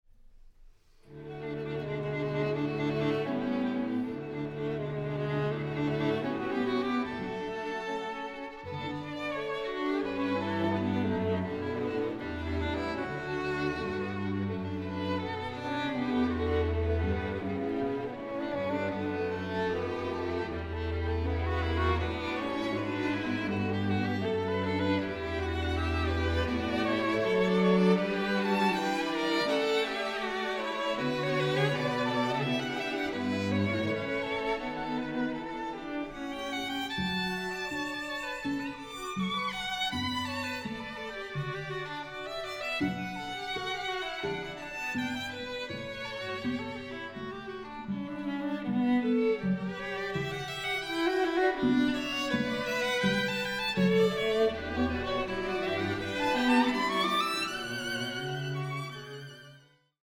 Seine Musik ist frei, rund, schön und voller Vitalität.